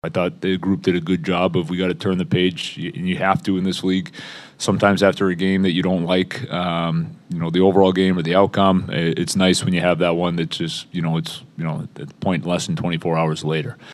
Coach Dan Muse says the Pens moved on quickly from the disappointment of Saturday’s shootout loss to the New York Rangers.